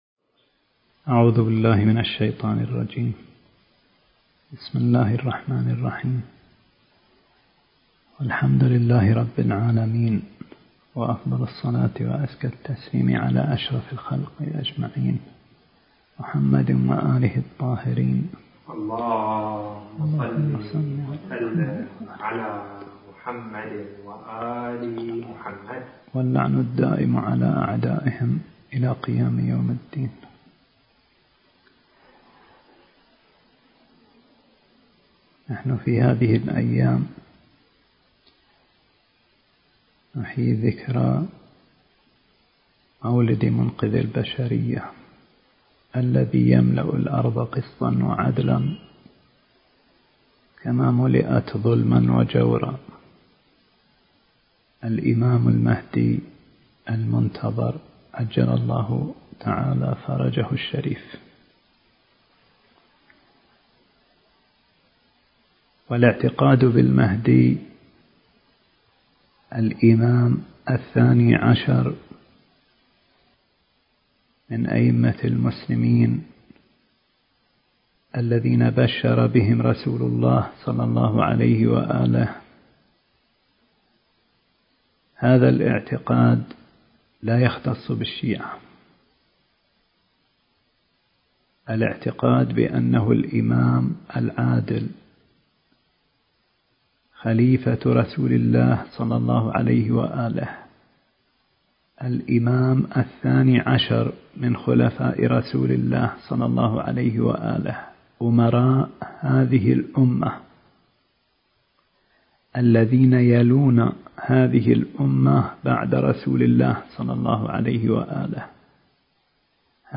التاريخ: 2021 - حفل مولد الإمام المهدي (عجّل الله فرجه) في الخامس عشر من شعبان